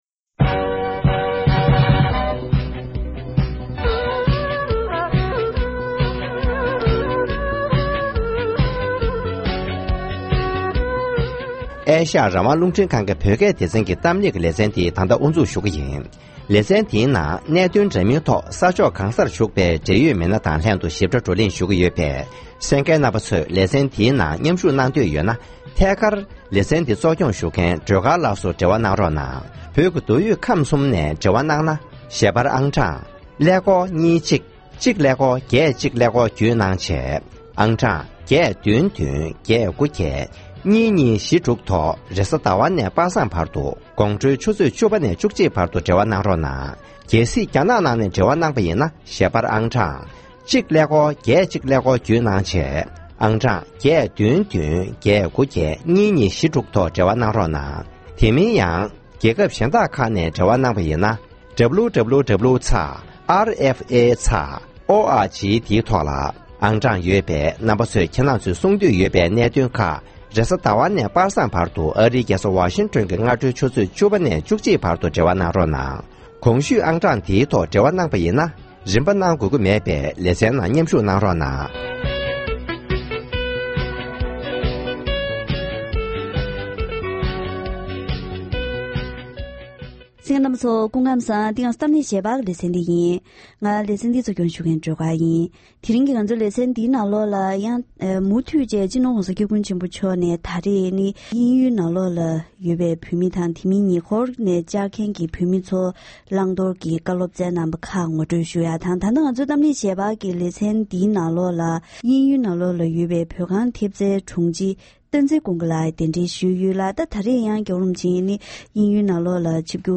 ༸གོང་ས་མཆོག་ནས་དབྱིན་ཇིའི་རྒྱལ་ས་ལོན་ཌན་དུ་མང་ཚོགས་ལ་བཀའ་སློབ་གནང་བ།